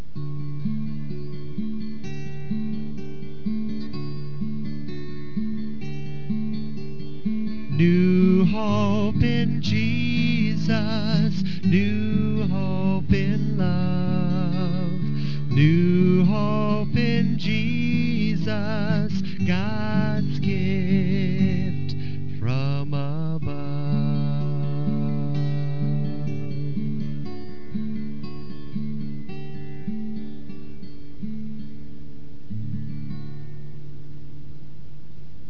PS:  There is also a power point presentation made to synchronize with this production and also a short theme song for the intro and exit to the production.
newhopesong.WAV